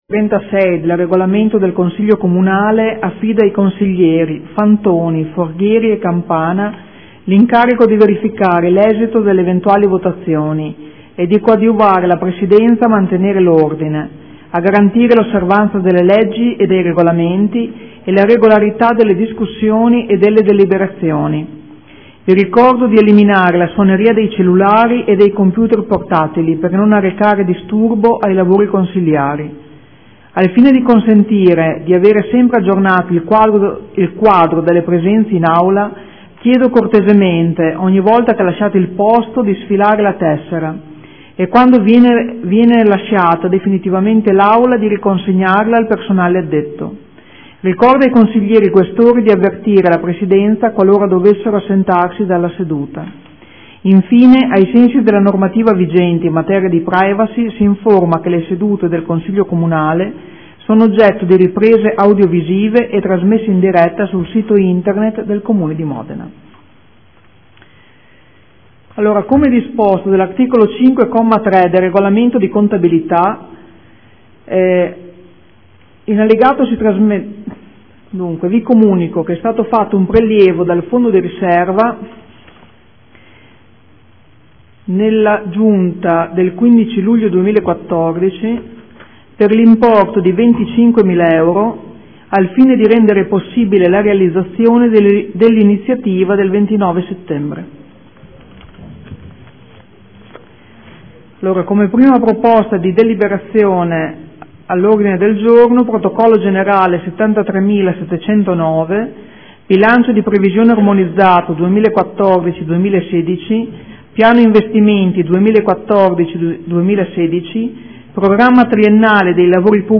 Seduta del 31/07/2014.